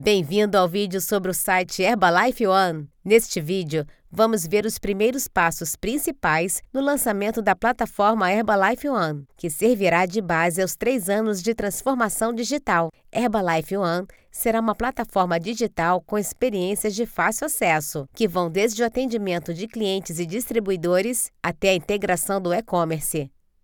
I have a natural and versatile voice, free from vices or exaggerations, with neutral Brazilian Portuguese suitable for different age groups and styles.
I have an isolated home studio treated with professional equipment: AT2020 mic and Focusrite Scarlett card.
Sprechprobe: eLearning (Muttersprache):